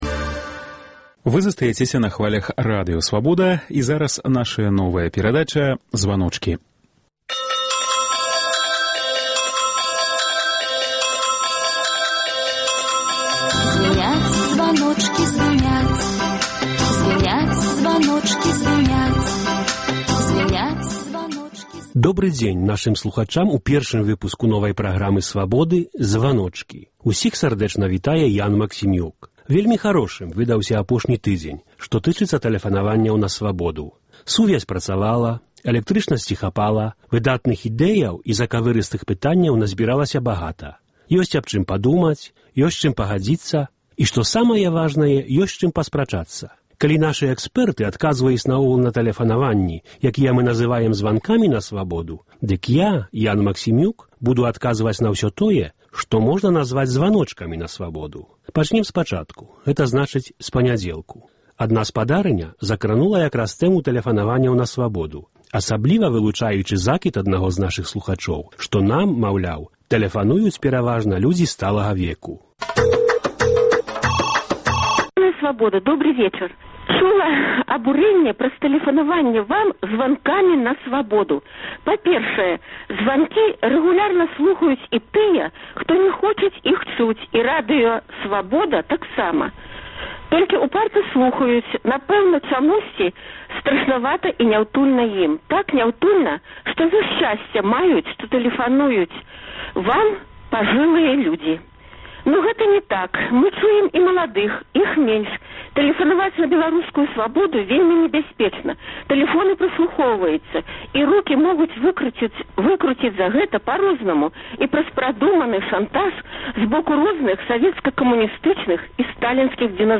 Вось праблемы, якія хвалююць нашых слухачоў у званках на "Свабоду".